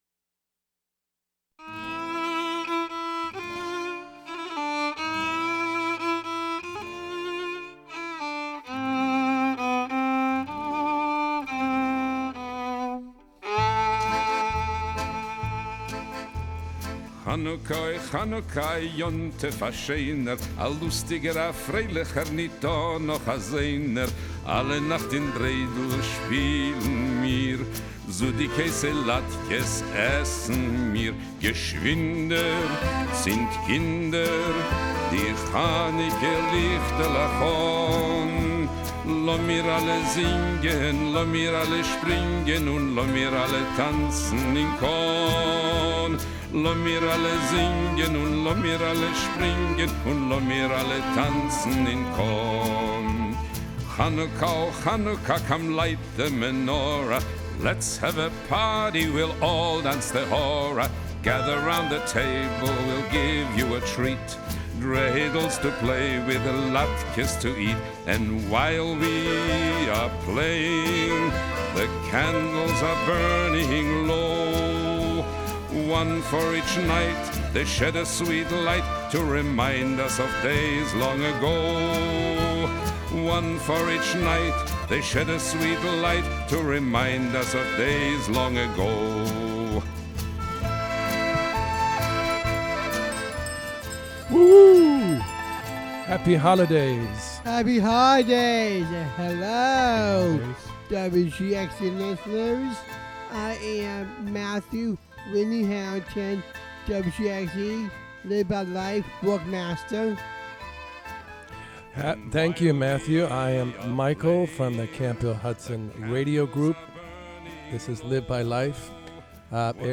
Members of the Camphill Hudson Radio Group from Camphill Hudson make their own radio. In this episode, the group reflects on and looks forward to various holidays and events in December 2025 that we participated in.